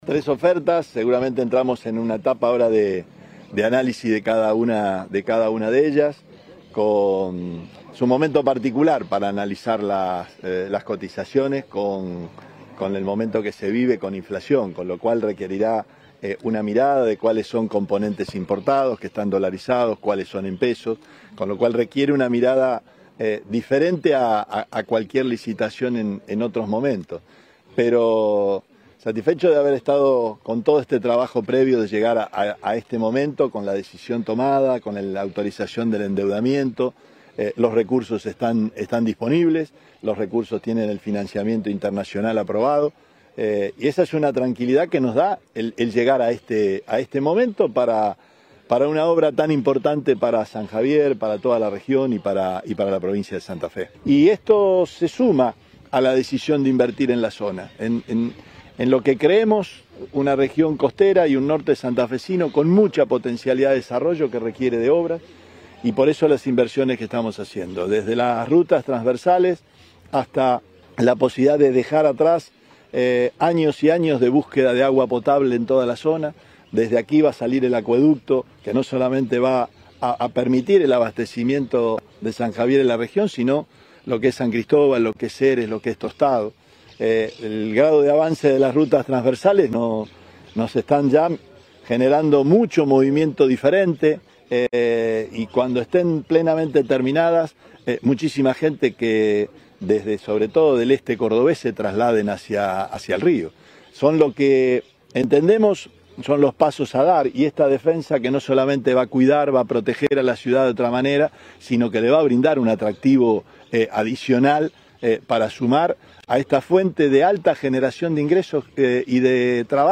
Declaraciones Perotti en el acto de apertura de sobres de la Defensa San Javier